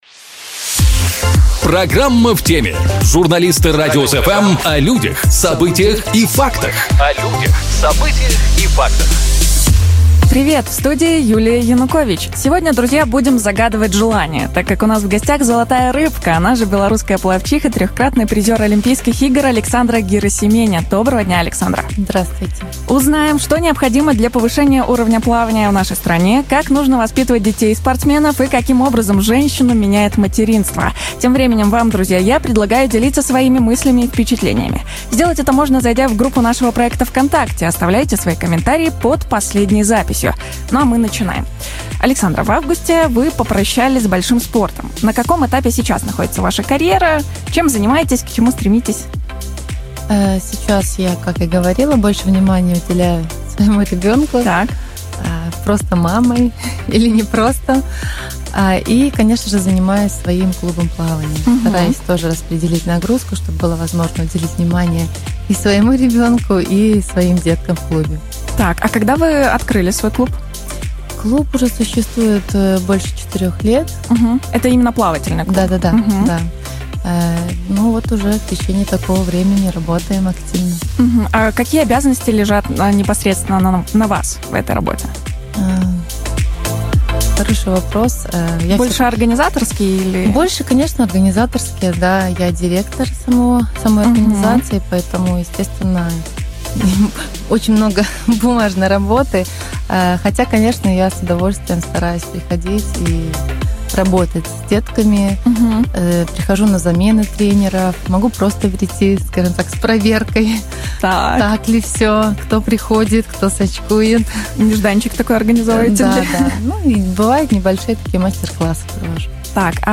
В студии "Радиус FМ" - "золотая рыбка", она же белорусская пловчиха, трехкратный призер Олимпийских игр Александра Герасименя.